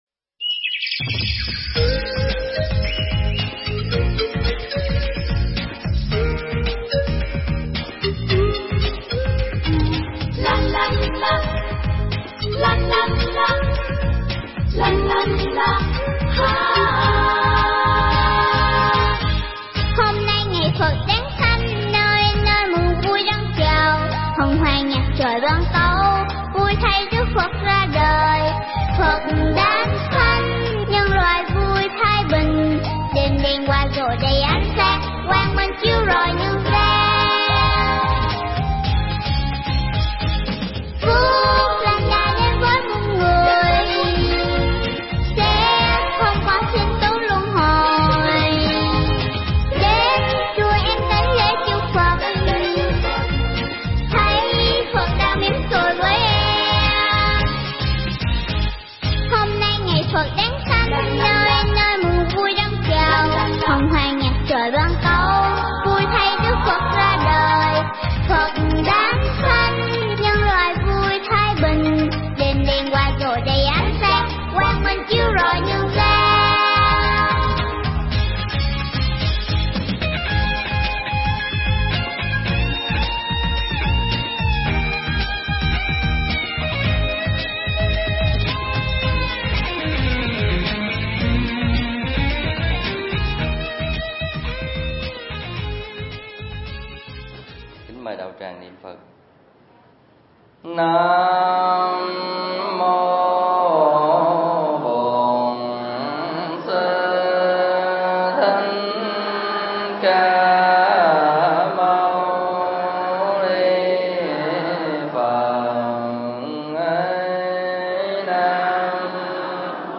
Thuyết Giảng
giảng tại chùa Ấn Quang (quận 10, HCM)